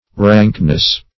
Rankness \Rank"ness\, n. [AS. rancness pride.]